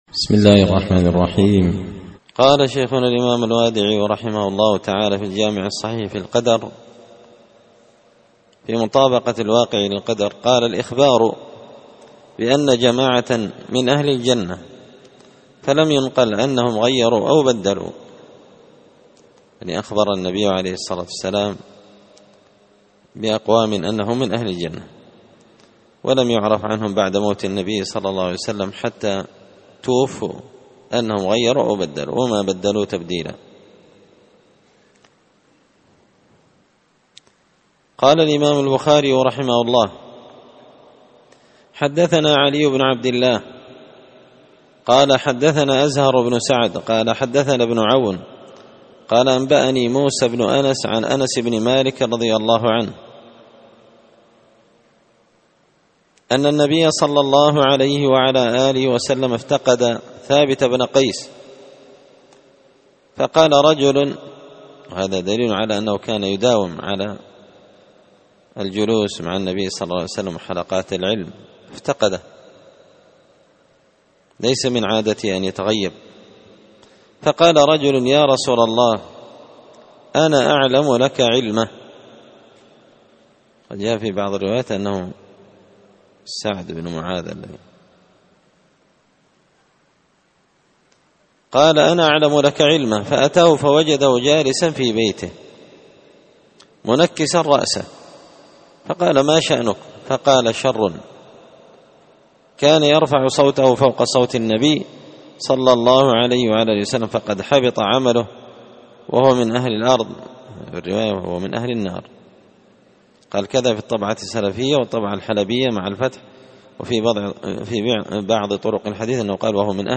الدرس 107 فصل من مطابقة الواقع على القدر
دار الحديث بمسجد الفرقان ـ قشن ـ المهرة ـ اليمن